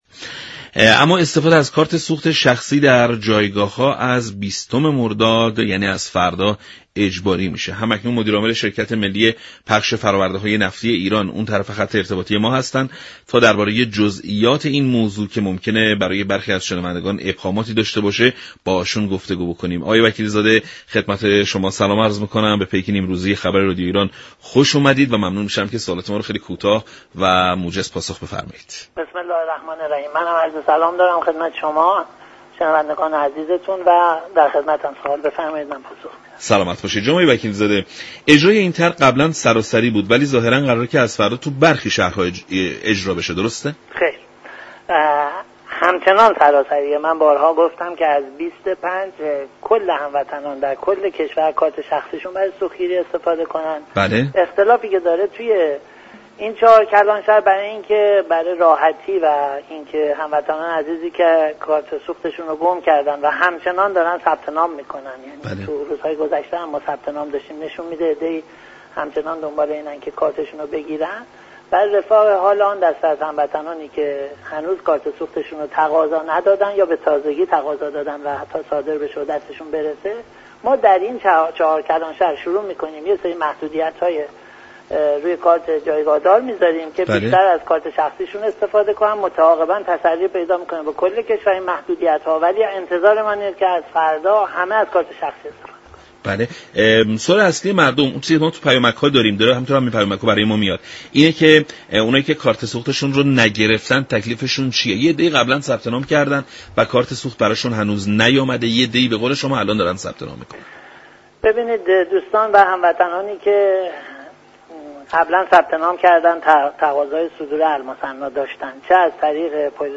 در گفت و گو با رادیو ایران